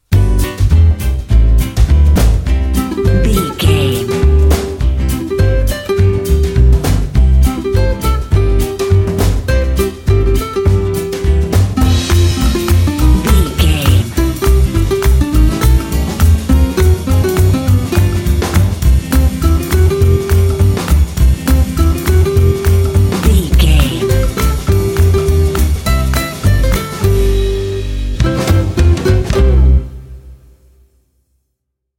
Ionian/Major
playful
uplifting
cheerful/happy
drums
acoustic guitar
bass guitar